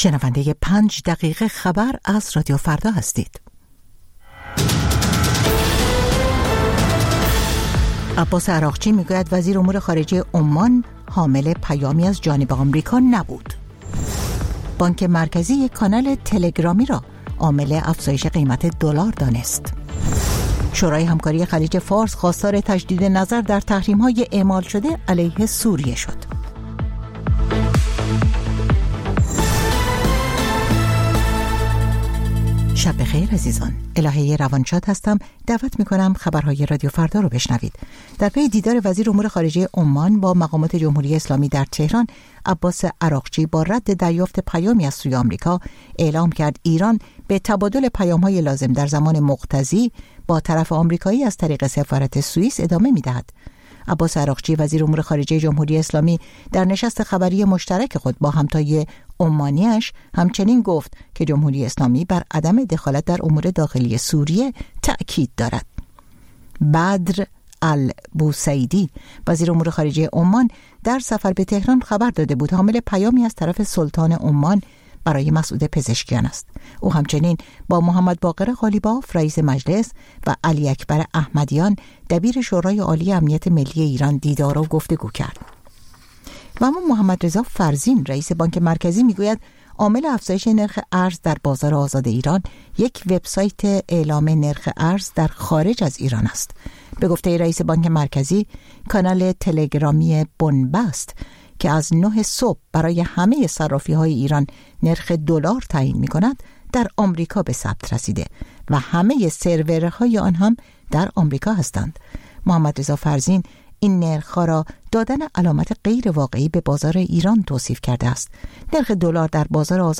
سرخط خبرها ۲۱:۰۰